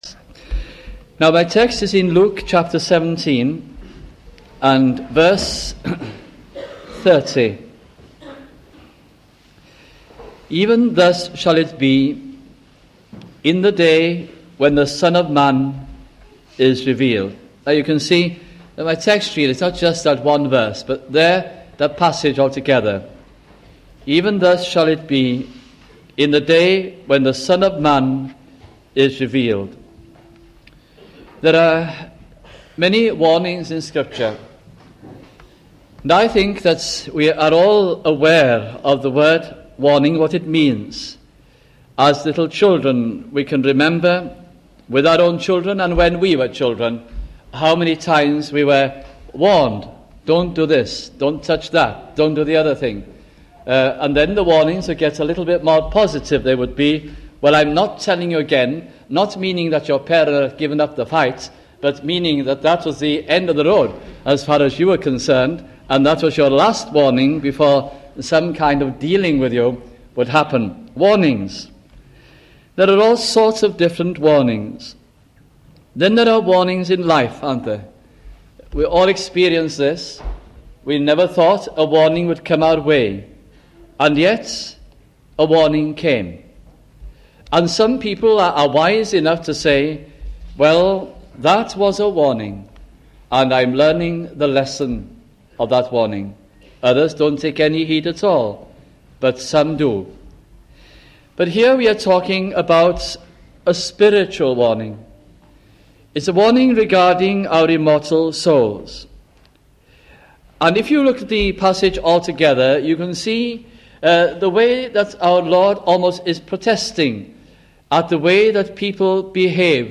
» Luke Gospel Sermons